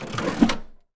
Menu Statsscreen Flipin Sound Effect
menu-statsscreen-flipin.mp3